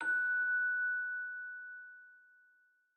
celesta1_6.ogg